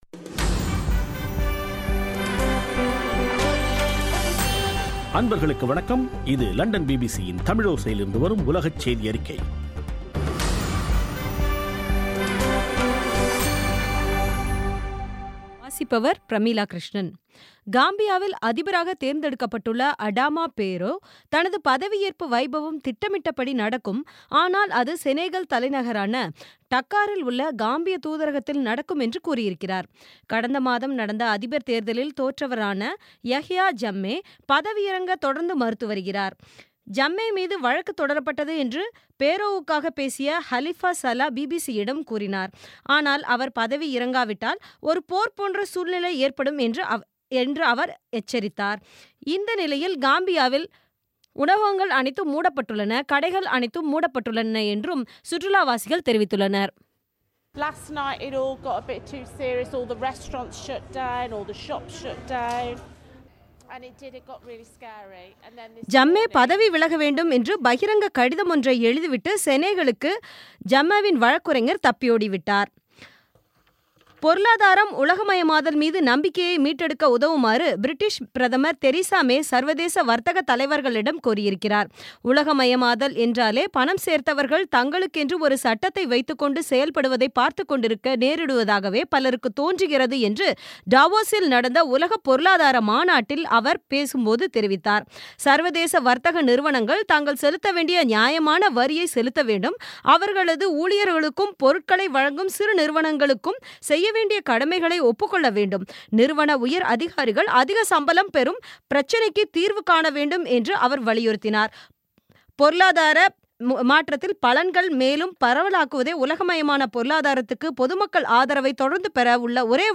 பிபிசி தமிழோசை செய்தியறிக்கை (19/01/2017)